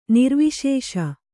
♪ nirviśeṣa